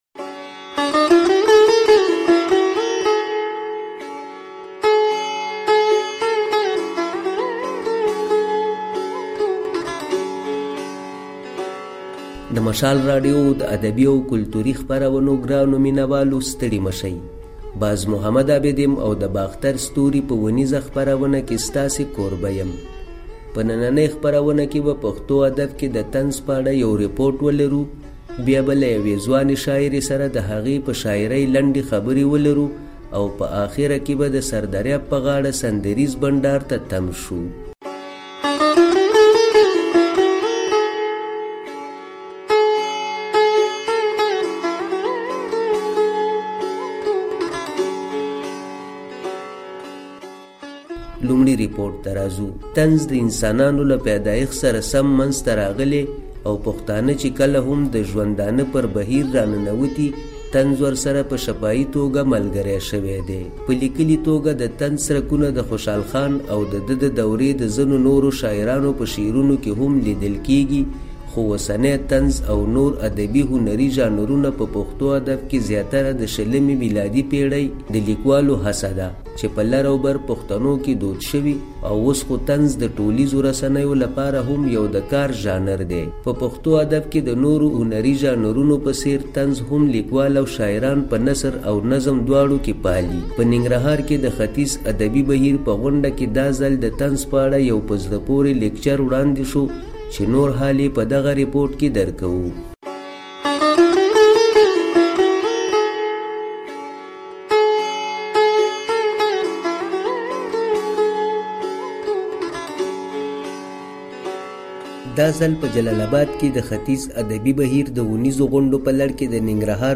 د باختر ستوري په خپرونه کې د طنز په اړه يو ريپوټ، له يوې ځوانې شاعرې سره د هغه په شاعرۍ مرکه او د سردریاب د غاړې سندريز بنډار ځای شوي دي